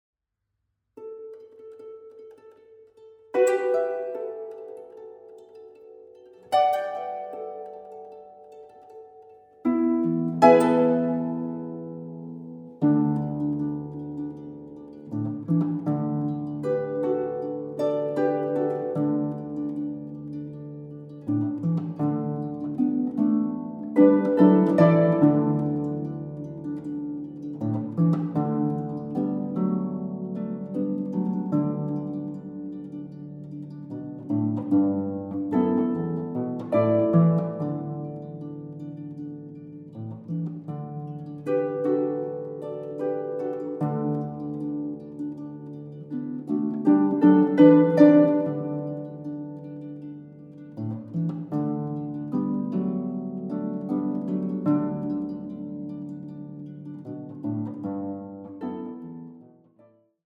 Contemporary Music for Harp
Harp